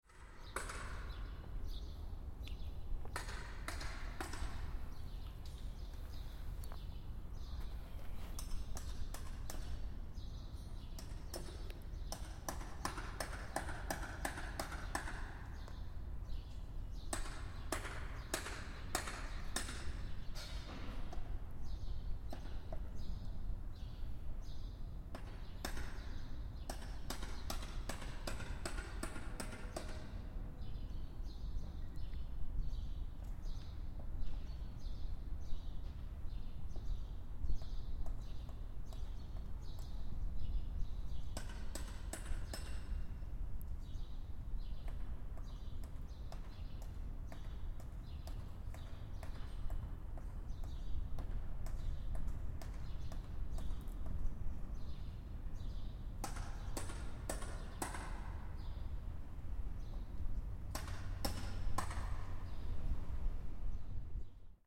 Here’s some ambiance from the courtyard. A lonely construction worker.
taskisla.mp3